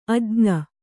♪ ajña